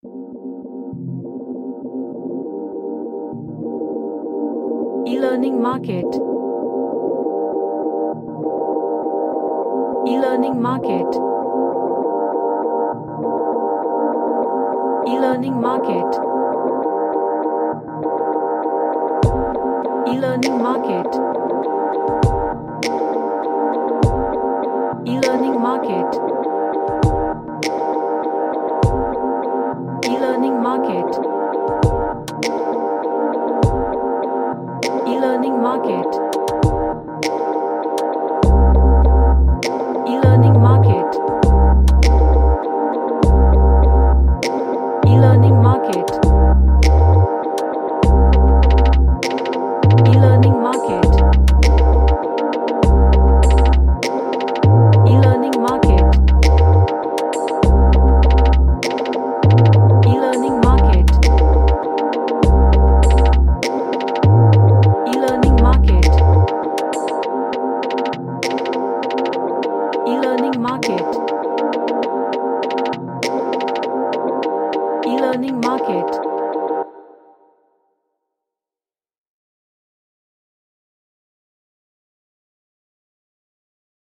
A elctric ambient pop track
Magical / Mystical